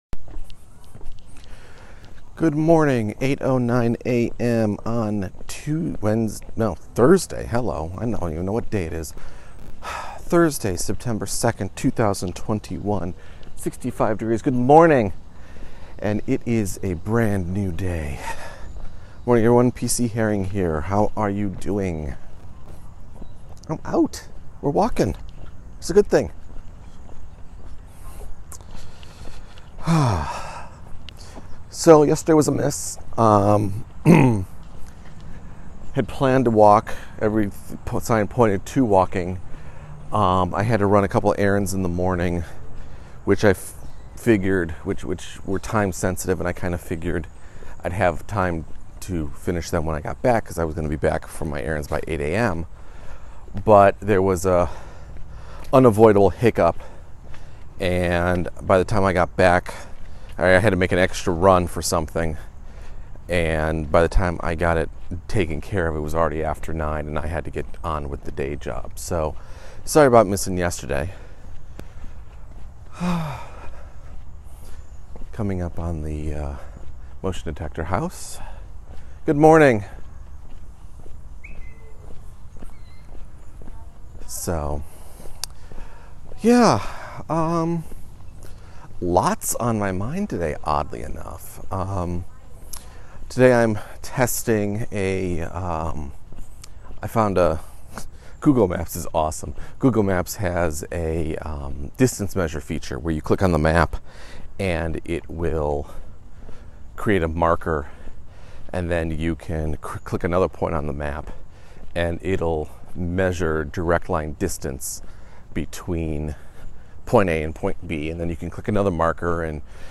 A bit of a disjointed walk and talk interrupted by a beagle bouncing off my legs, neighborhood kids loosing control of their own dog, and a flatbed truck picking up a cargo box and blocking the path of a school bus.